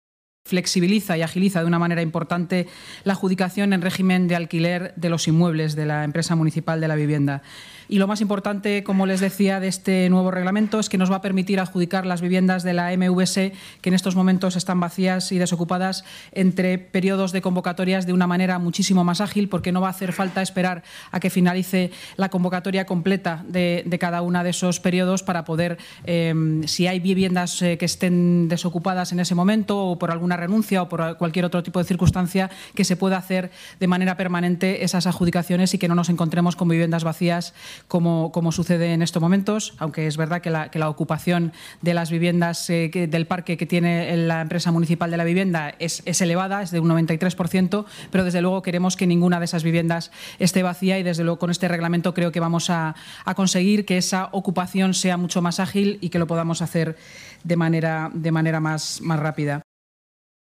Inmaculada Sanz durante la rueda de prensa posterior a la Junta de Gobierno